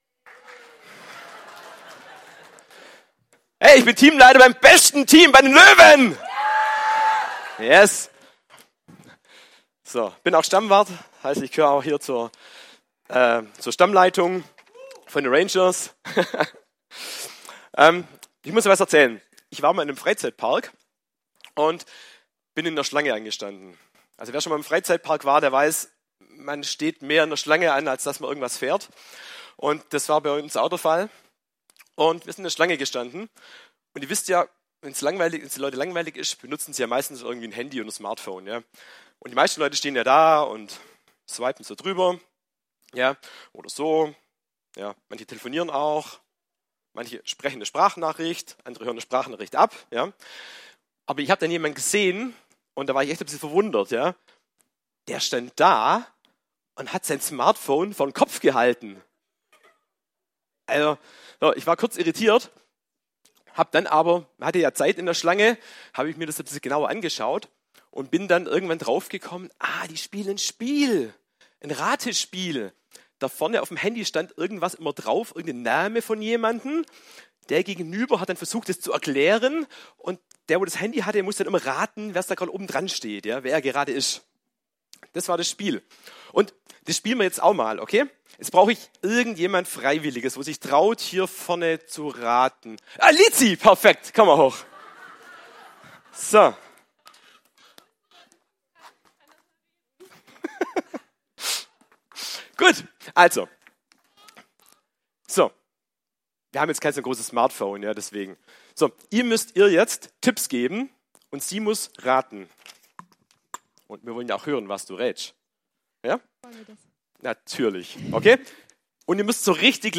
Predigt-Details - FCG Ecclesia Laupheim
Royal-Ranger Gottesdienst Predigttext: Römer 6,23